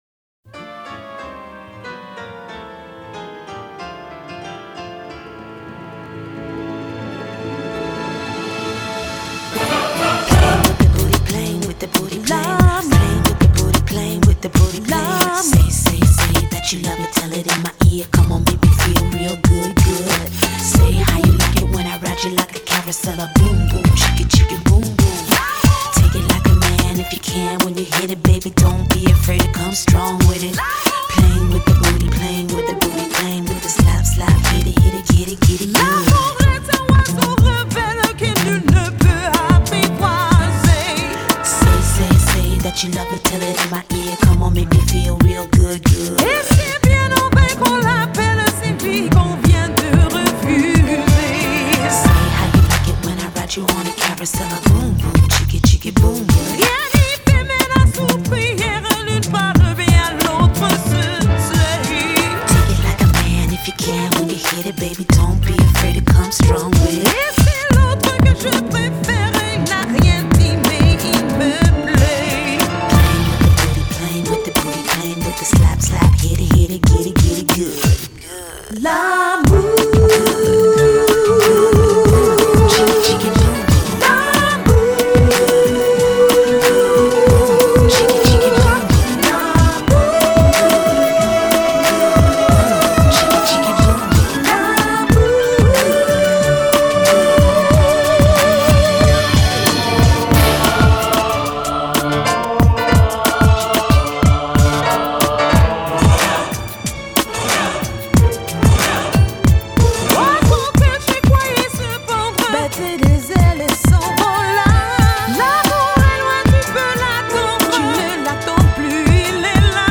Весёленькая